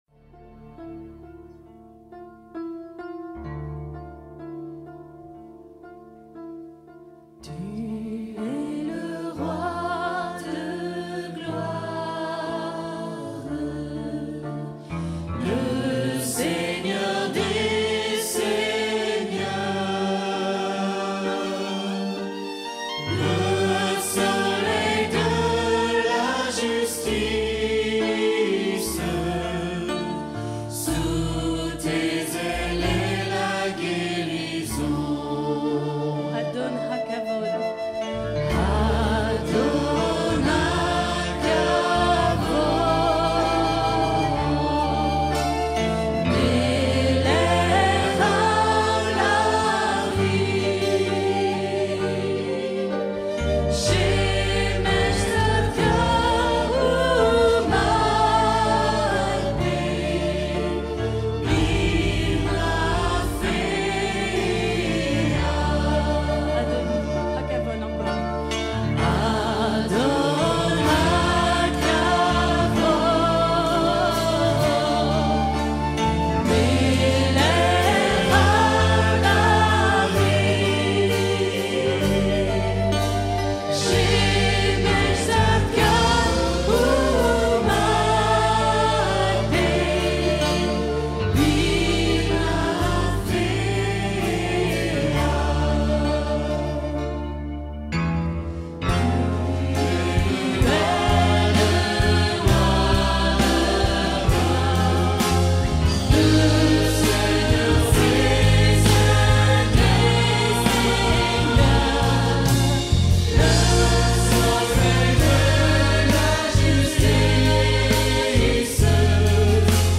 Chants de veillée et de louange